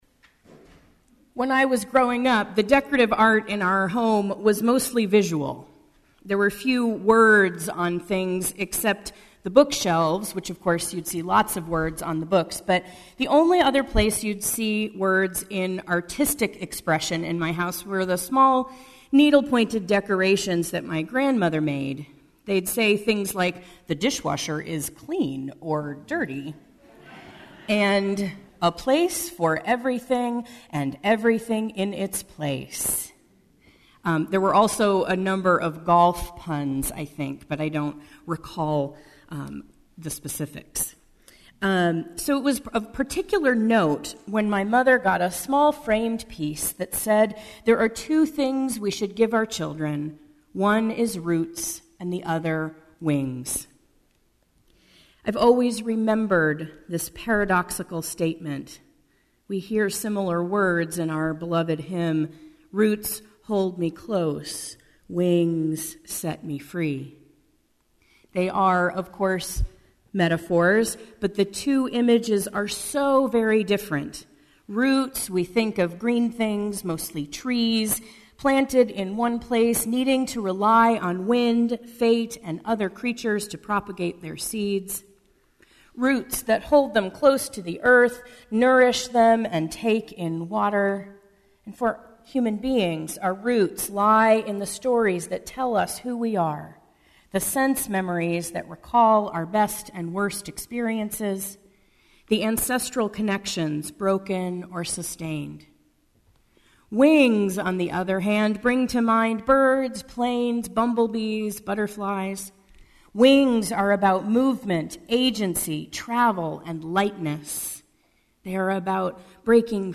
Unitarian Universalism is a covenantal faith, not a creedal one. In the second of two sermons on covenant, we’ll explore the theological implications of our covenantal roots, and how to live into the promises we make to one another today.